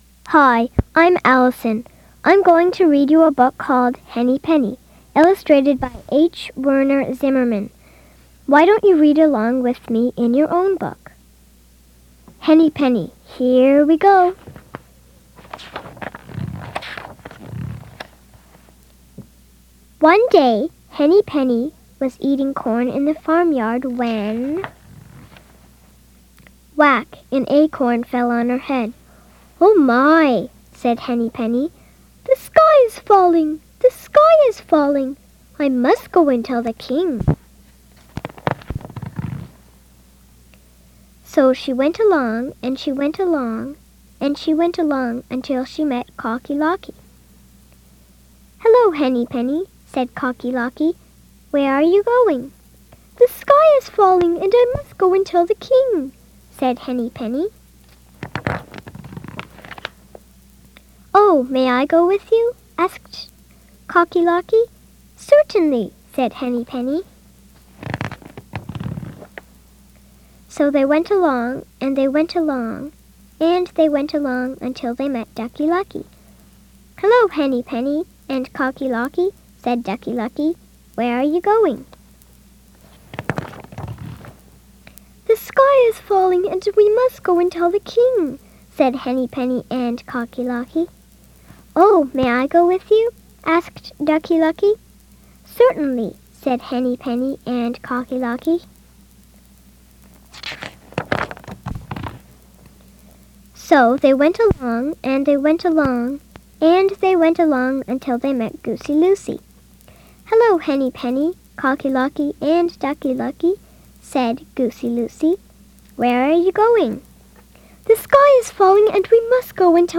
DOWNLOAD AUDIO NOVEL